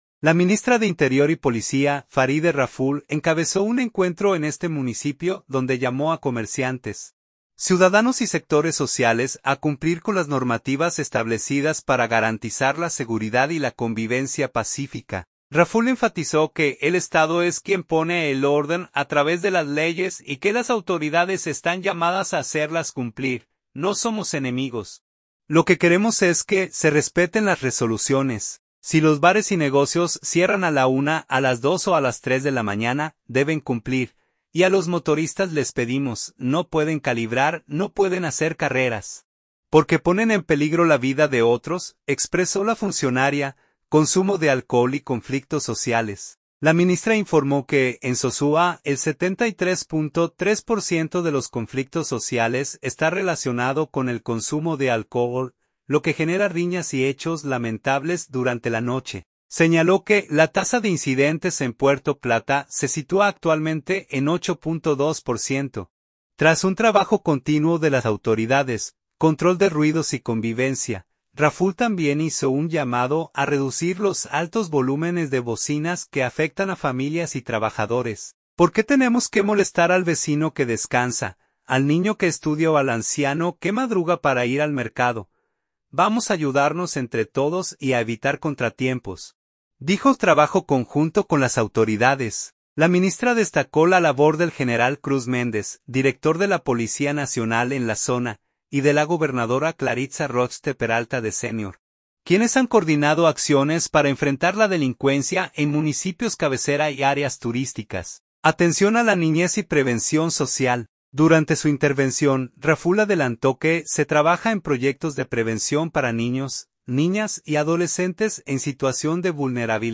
Sosúa, Puerto Plata.- La ministra de Interior y Policía, Faride Raful, encabezó un encuentro en este municipio donde llamó a comerciantes, ciudadanos y sectores sociales a cumplir con las normativas establecidas para garantizar la seguridad y la convivencia pacífica.